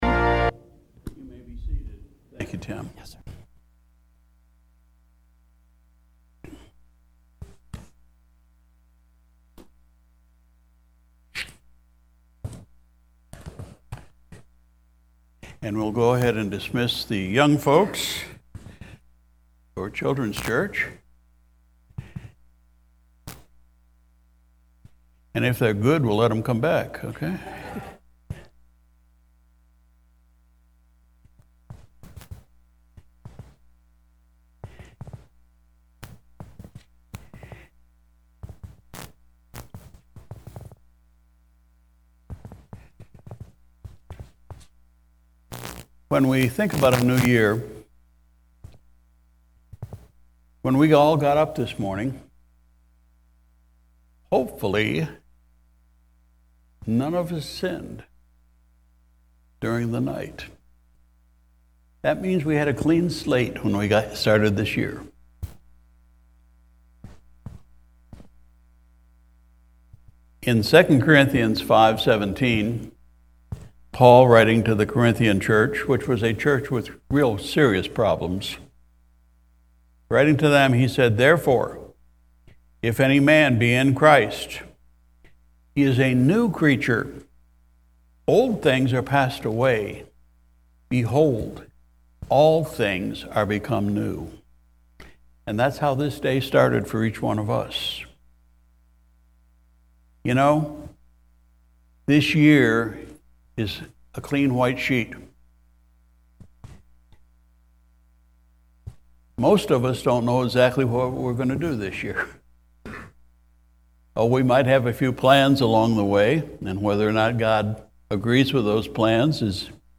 January 1, 2023 Sunday Morning Service Pastor’s Message: “All Things New”